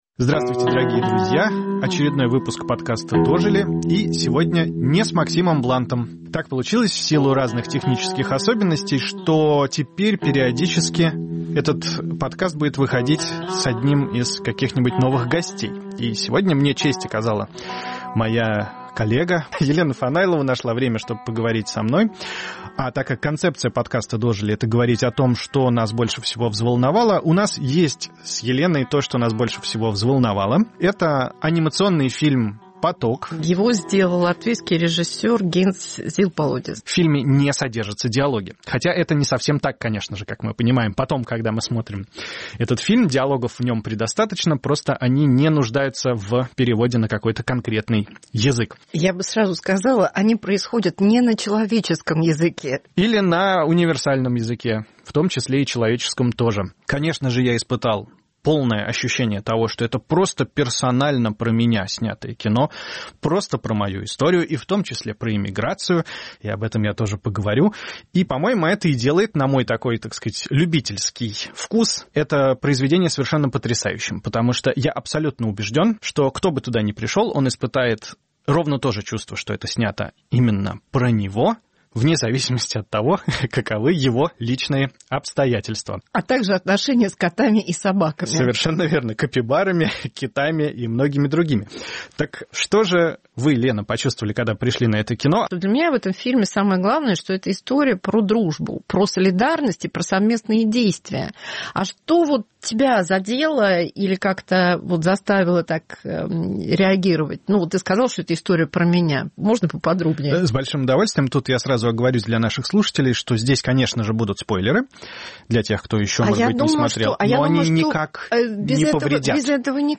В рассуждениях о жизни и смерти, одиночестве и дружбе, взрослении, ведущие быстро понимают, что говорят они не мультипликационных животных, а о самих себе. Повтор эфира от 2 февраля 2025 года.